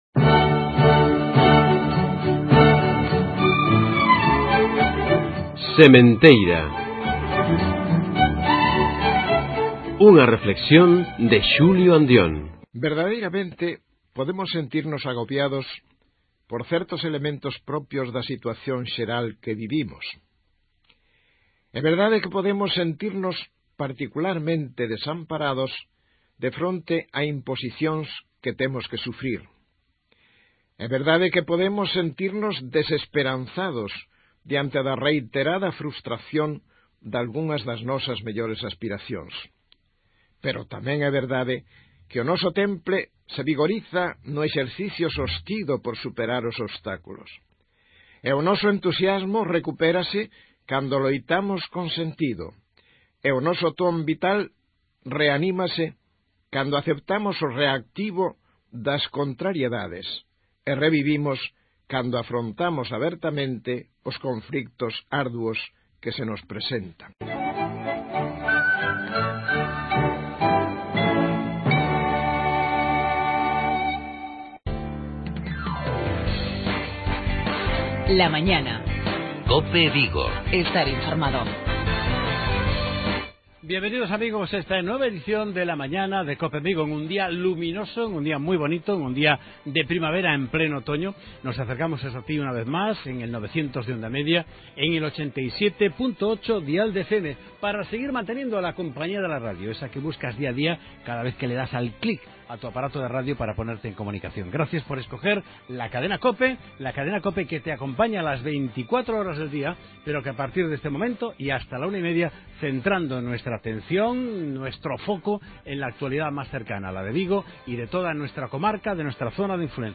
Redacción digital Madrid - Publicado el 14 oct 2015, 14:19 - Actualizado 19 mar 2023, 04:06 1 min lectura Descargar Facebook Twitter Whatsapp Telegram Enviar por email Copiar enlace Avance informativoEntrevista a :Elena Muñoz(Portavoz del grupo municipal del PP)Entrevista a:Ignacio López Chaves(Delegado Territorial de la Xunta de Galicia)